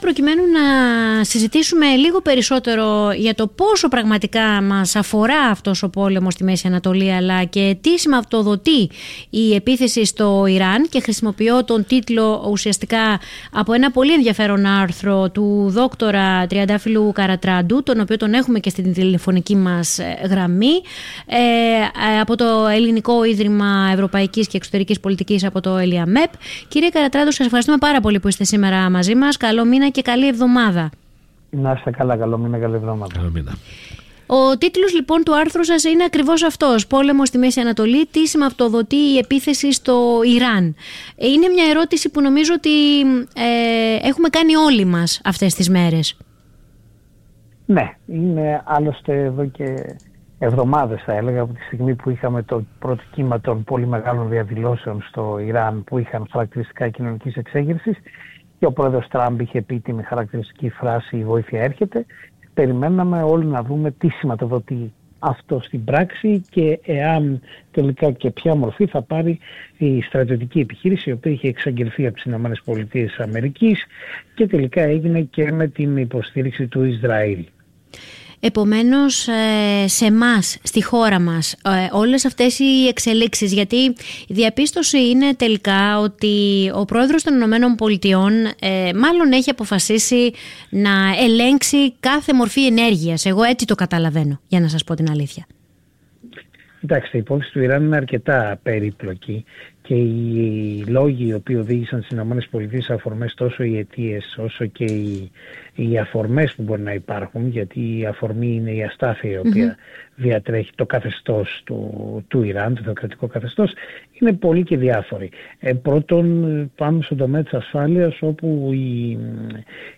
φιλοξενήθηκε και μίλησε στην εκπομπή του FORMEDIA RADIO 99.8